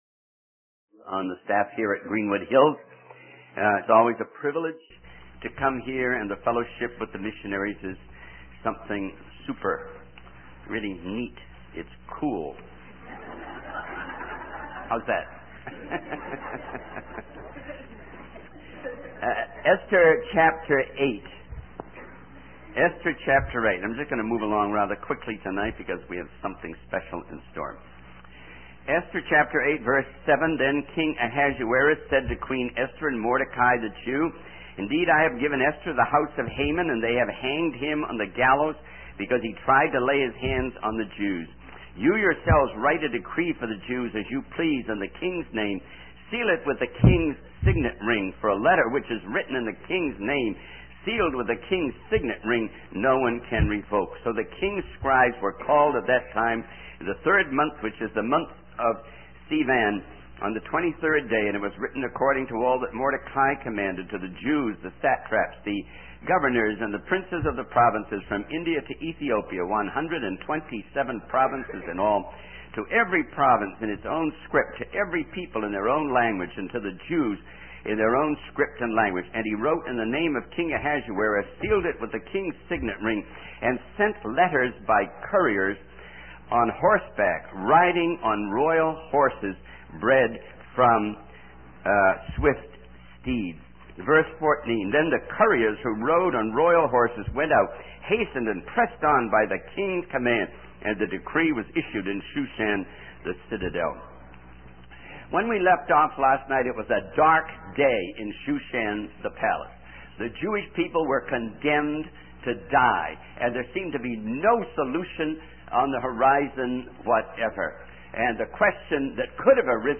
In this sermon, the speaker reflects on the significance of one's last words and the desire to leave a positive legacy. The sermon emphasizes God's continuous work on behalf of his people and the thrilling prospect of the good news spreading rapidly. The speaker also addresses the current state of the gospel and the need for Christians to actively participate in its dissemination.